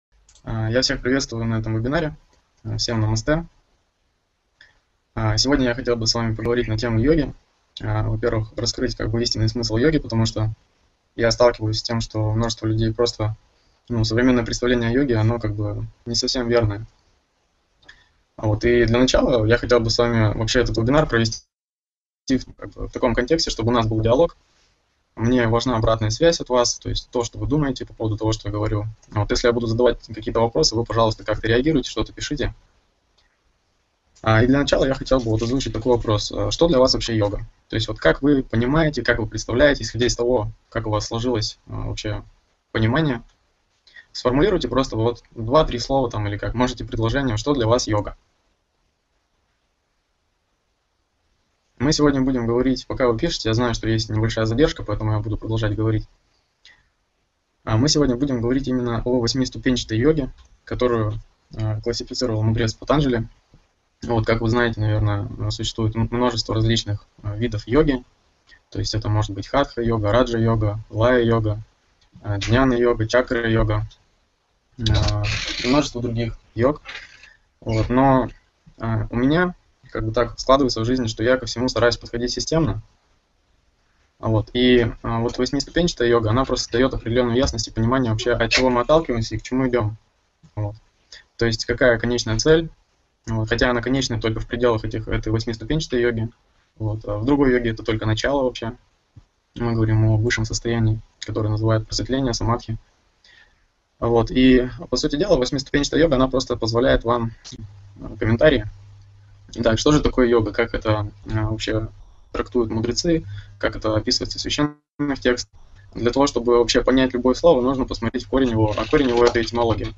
Извиняюсь не за самое лучше качество аудио записи, поскольку она 2013 года, но зато содержание хорошее.